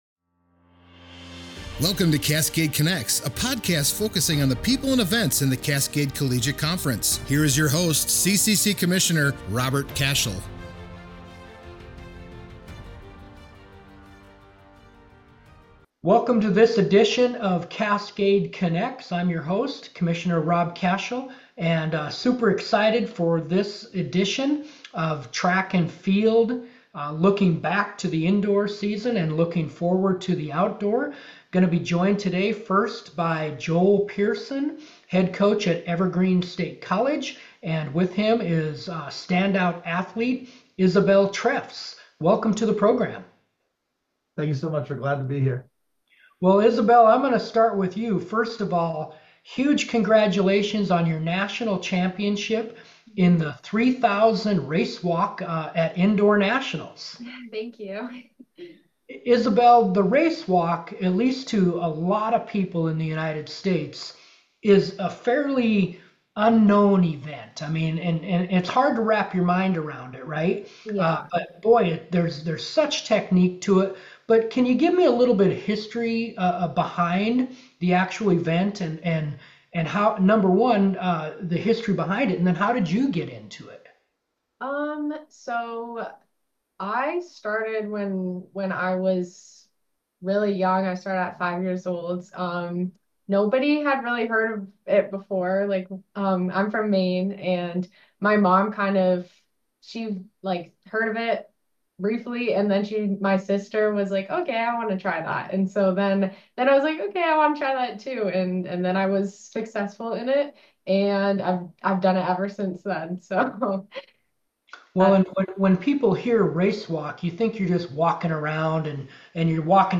talks with the recent Indoor Track & Field National Champions